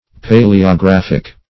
Search Result for " paleographic" : The Collaborative International Dictionary of English v.0.48: Paleographic \Pa`le*o*graph"ic\, Paleographical \Pa`le*o*graph"ic*al\, a. [Cf. F. pal['e]ographique.]
paleographic.mp3